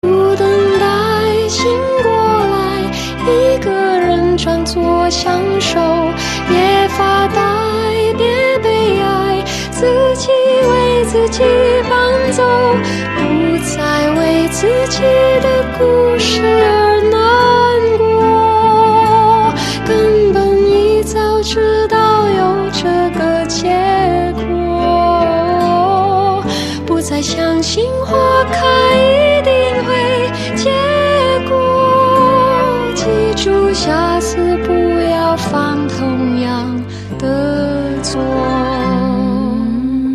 M4R铃声, MP3铃声, 华语歌曲 85 首发日期：2018-05-15 05:09 星期二